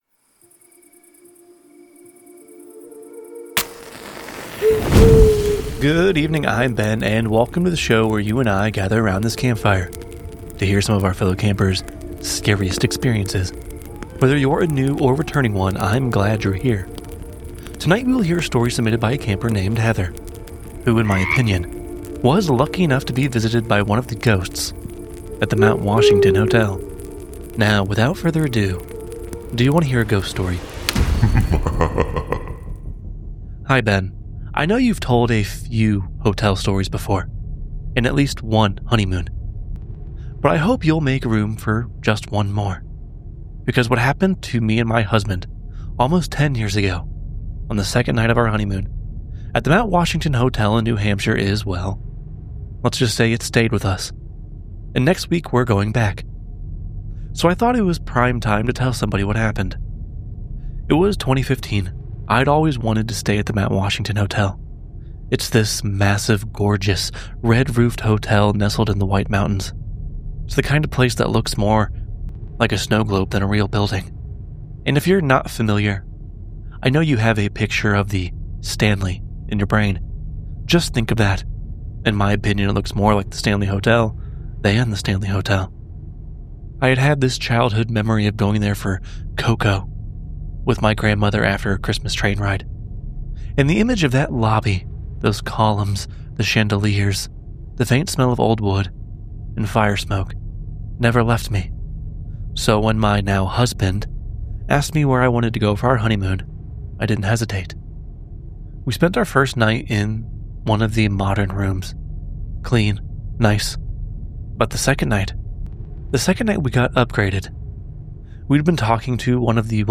Sound Design by: